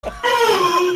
Noise